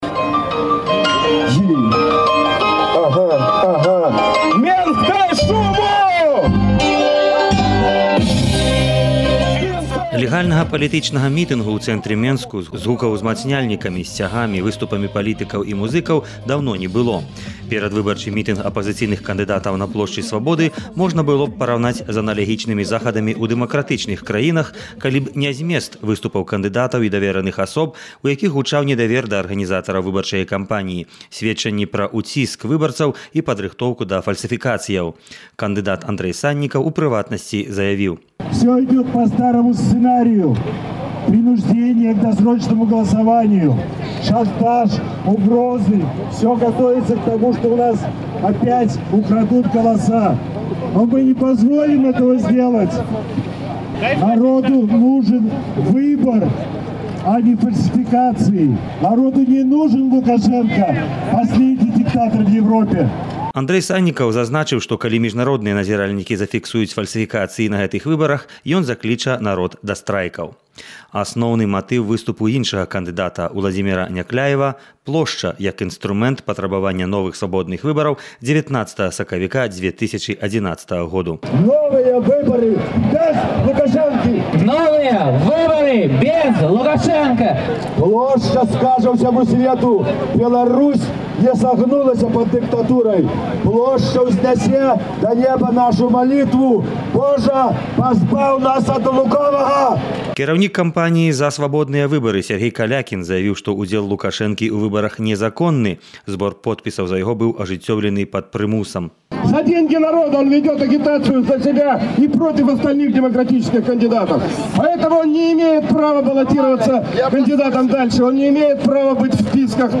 Рэпартаж